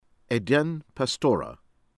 PASTORA, EDEN a-DEHN   pahs-TOH-rah